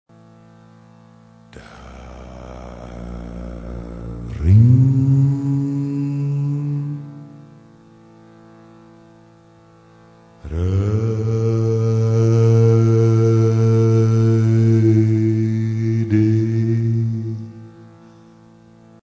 Het is een gezongen stuk die het hart activeert.